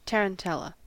Ääntäminen
US : IPA : /ˌtɛrənˈtɛlə/